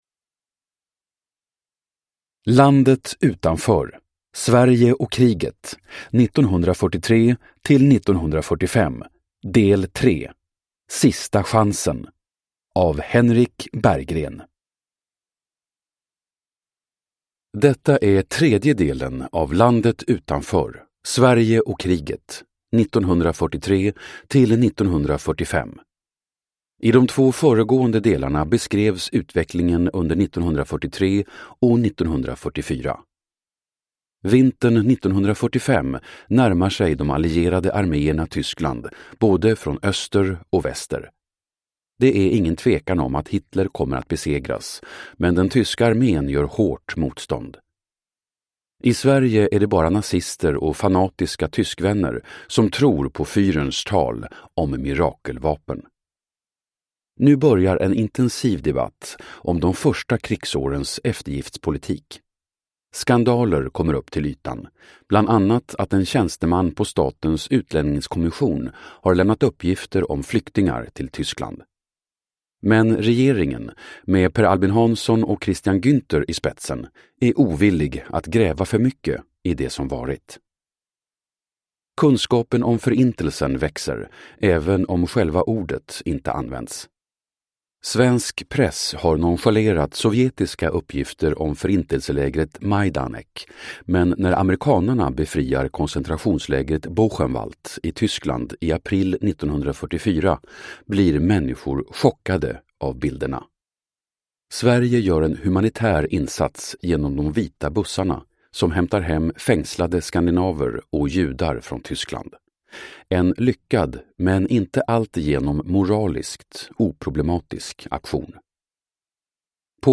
Landet utanför : Sverige och kriget 1943-1945. Del 3:3, Sista chansen – Ljudbok